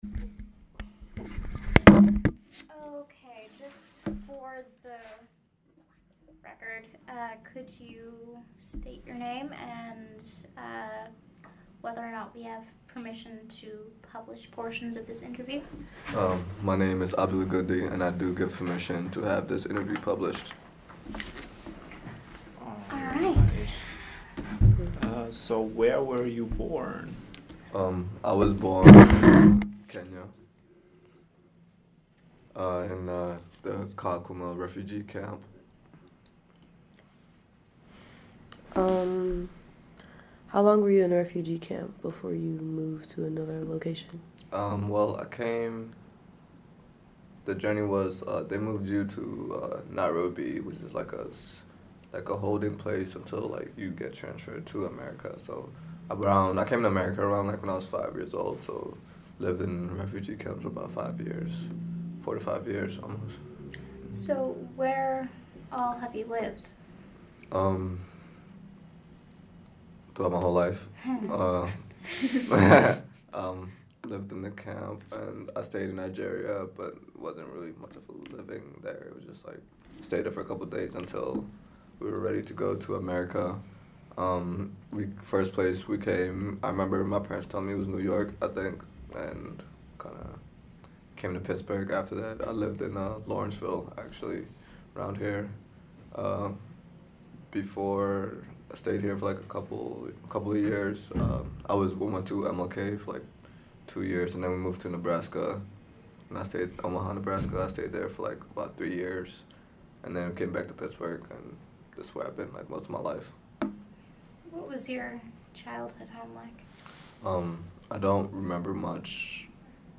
Somali-Bantu Oral History Project